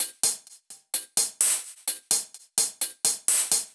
Index of /musicradar/french-house-chillout-samples/128bpm/Beats
FHC_BeatB_128-03_Hats.wav